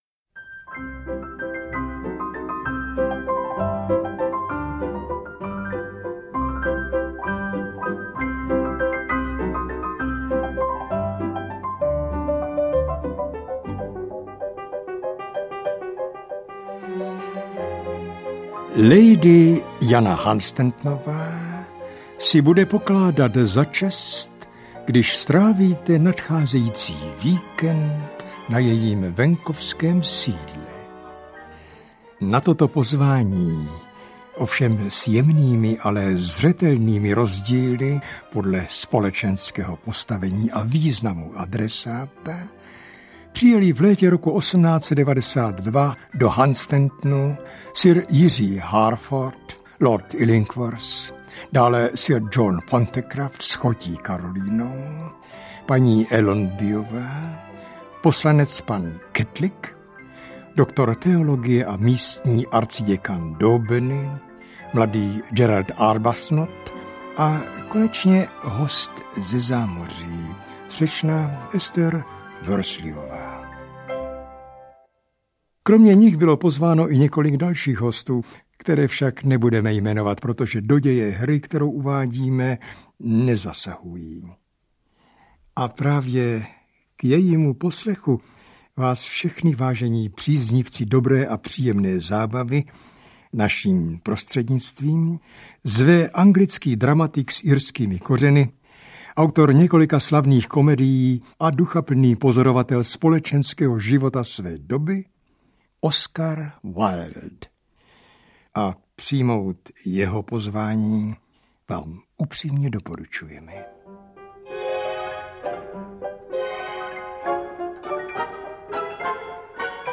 Bezvýznamná žena audiokniha
Ukázka z knihy